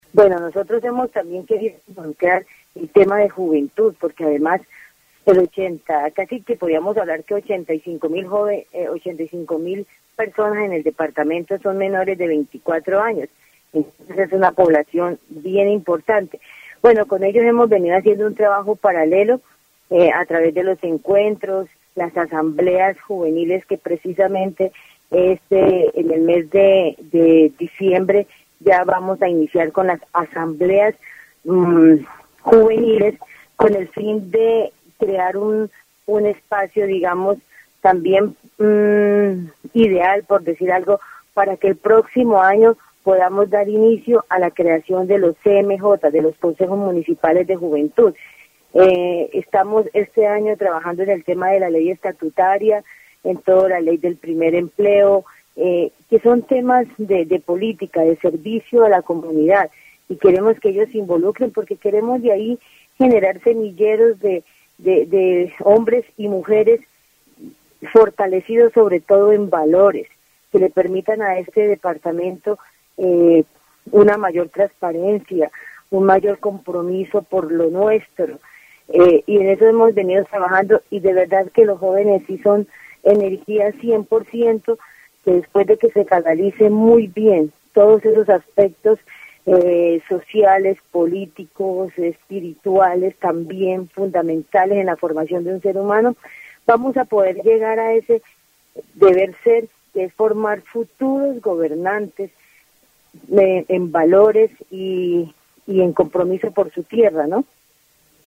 dc.subject.lembProgramas de radio